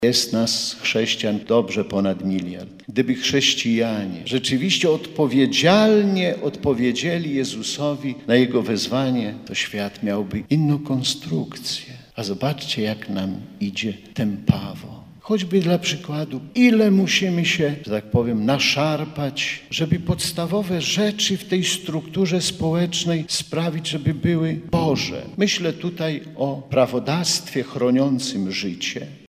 W homilii bp Kamiński podkreślił, że Królestwo Boże ma przede wszystkim charakter duchowy.